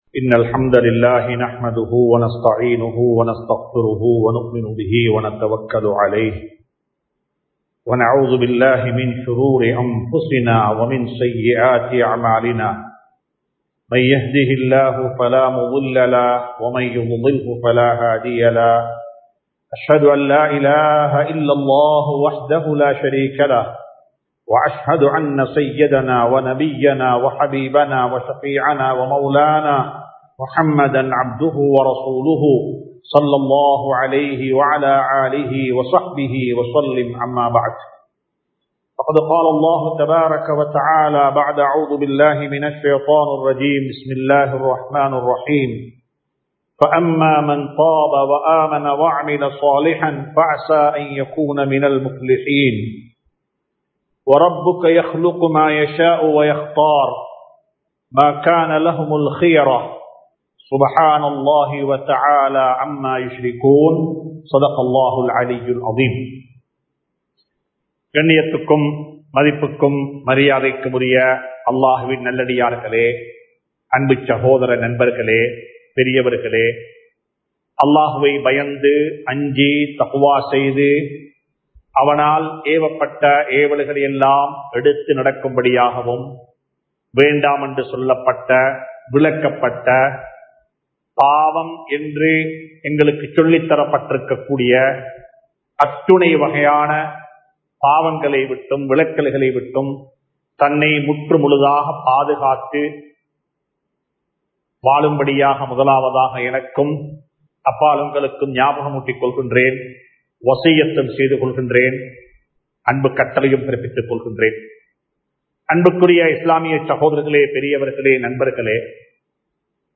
எம் சமூகத்தில் இன்னும் வாழ்கிறார்கள் | Audio Bayans | All Ceylon Muslim Youth Community | Addalaichenai
Muhideen (Markaz) Jumua Masjith